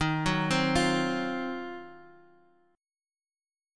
Ebm6add9 Chord
Listen to Ebm6add9 strummed